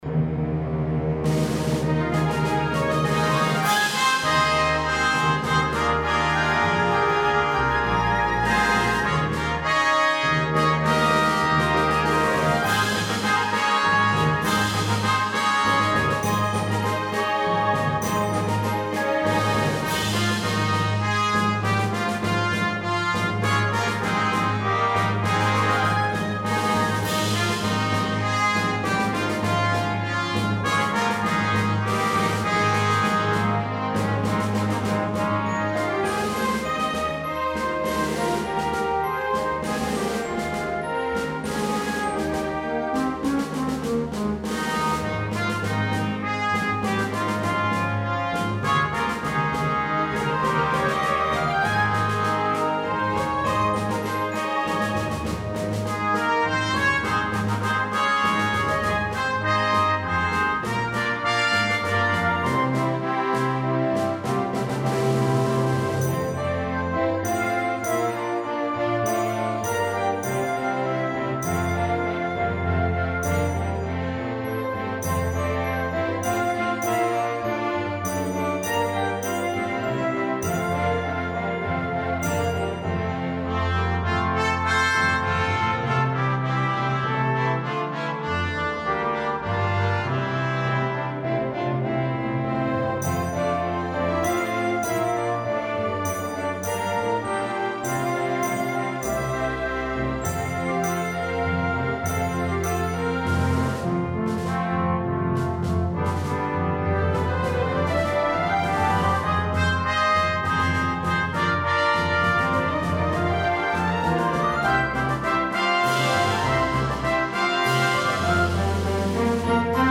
A fresh and triumphant arrangement